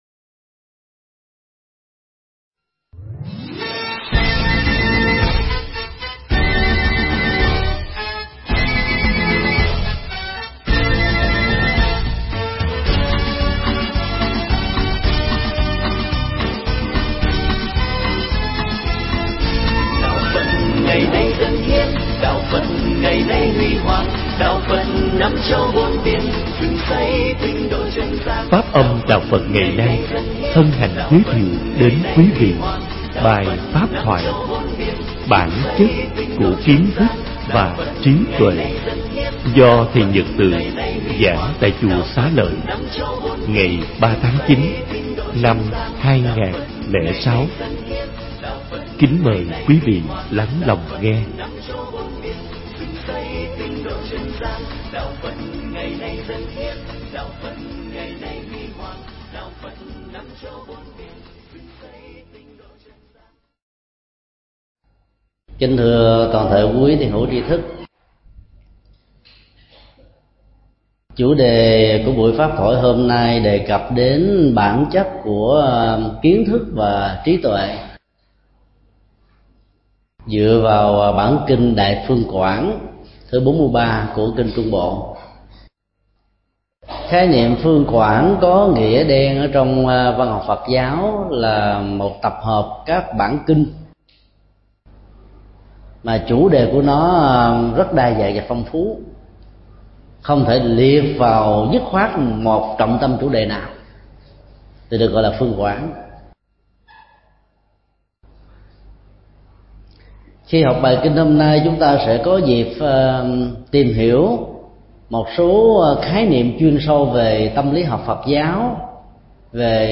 Mp3 Pháp thoại Kinh Trung Bộ 043
tại Chùa Xá Lợi